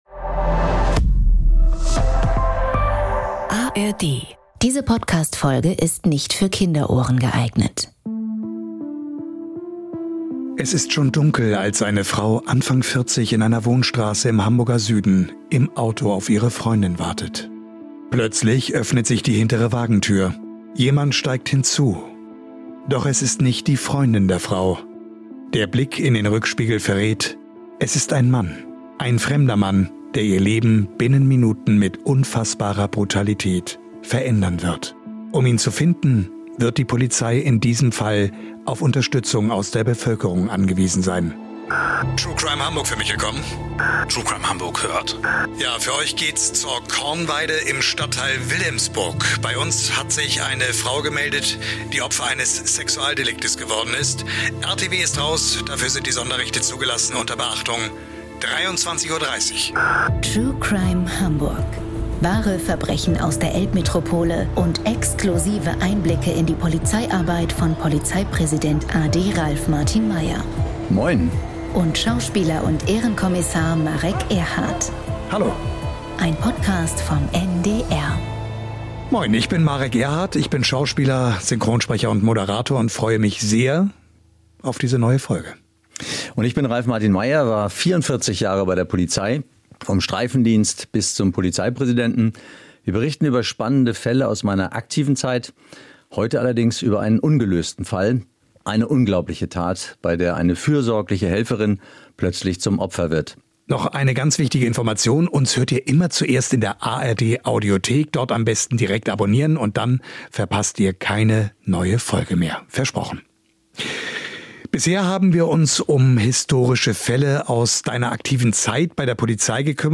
In dieser Folge sprechen Schauspieler und Ehrenkommissar Marek Erhardt und Hamburgs Polizeipräsident a.D. Ralf Martin Meyer über den aktuellen Ermittlungsstand, den Balanceakt bei Öffentlichkeitsfahndungen und sie bitten euch um Unterstützung.